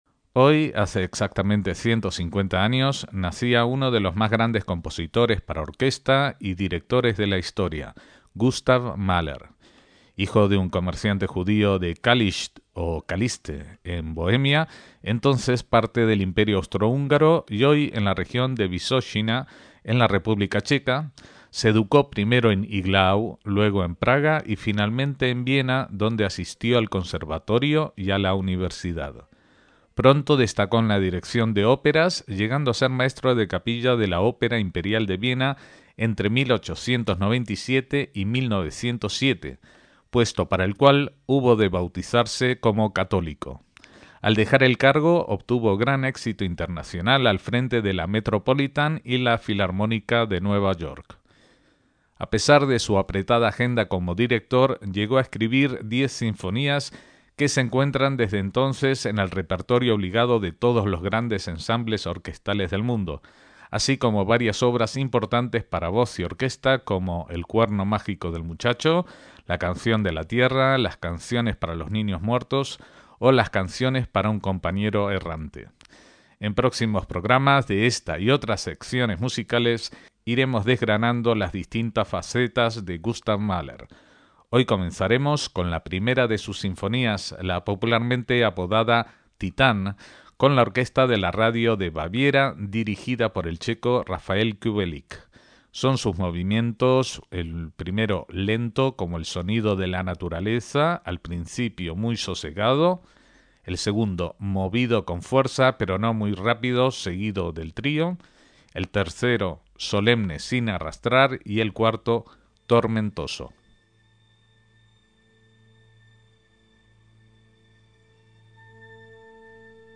Primera Sinfonía por Rafael Kubelik y la orquesta de la Radio de Bavaria